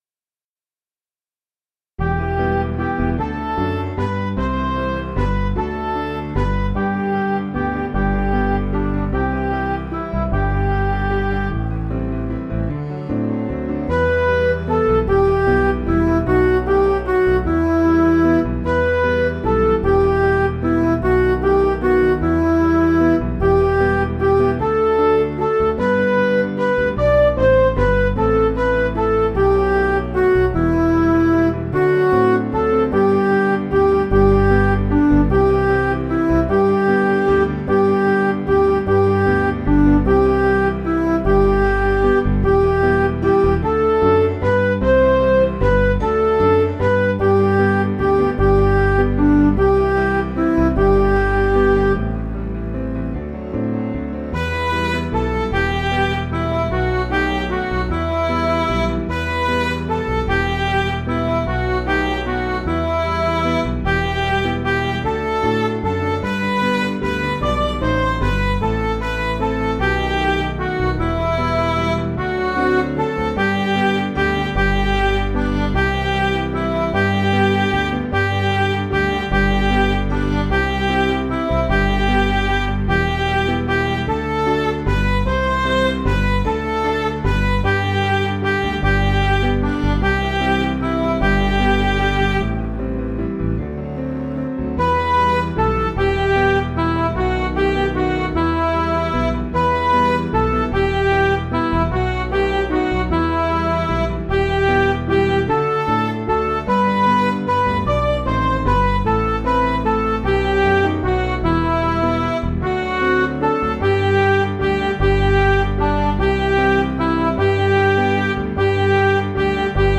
Piano & Instrumental
(CM)   5/G